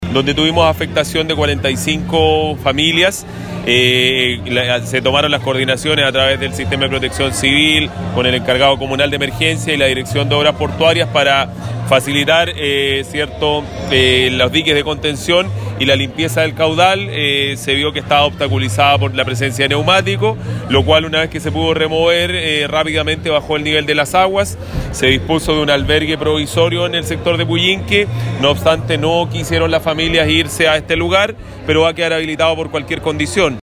Así lo confirmó el Intendente de Los Ríos, César Asenjo, quien explicó que tras el despeje de la obstaculización del estero, el agua comenzó a descender, añadiendo que se habilitó un albergue para los damnificados, quienes no habrían querido hacer uso del recinto, el cual igualmente quedó habilitado ante una eventual nueva contingencia.